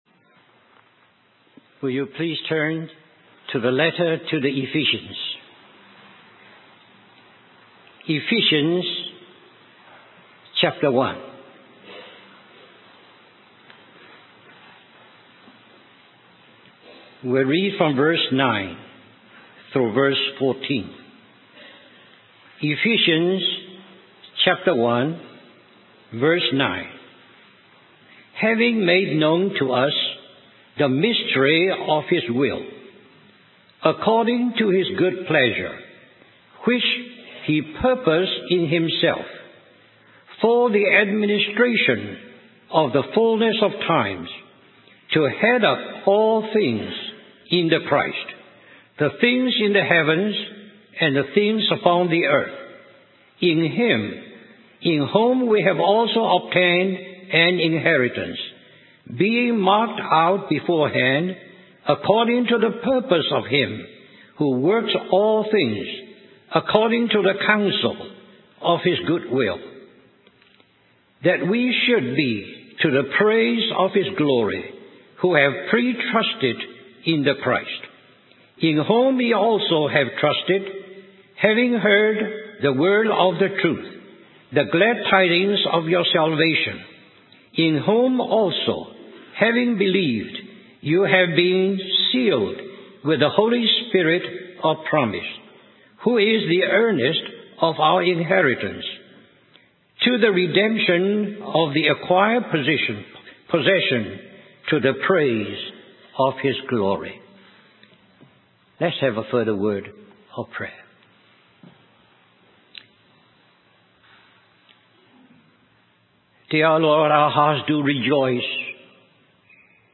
In this sermon, the speaker shares a story about an elderly lady he met in India who had a unique ministry.